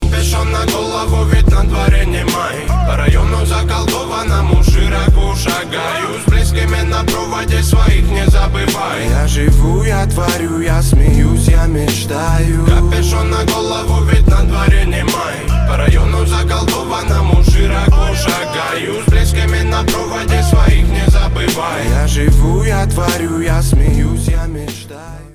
• Качество: 320, Stereo
позитивные
мужской вокал
русский рэп